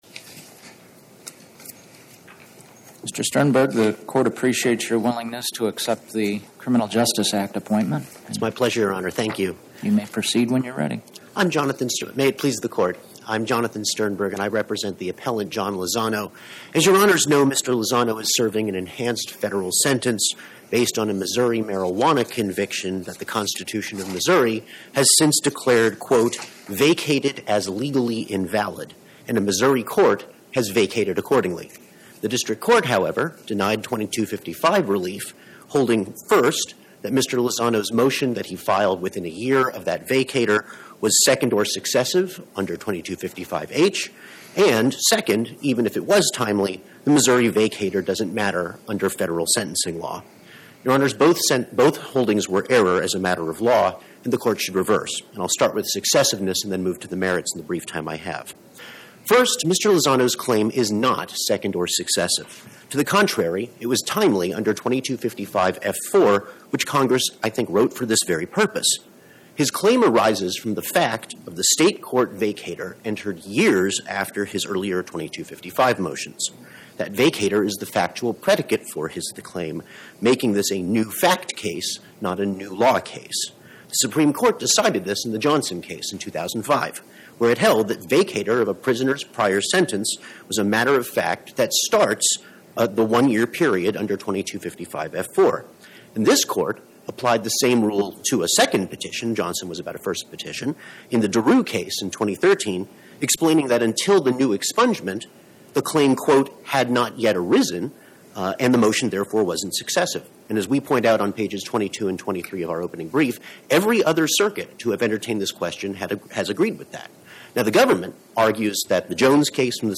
Oral argument argued before the Eighth Circuit U.S. Court of Appeals on or about 12/16/2025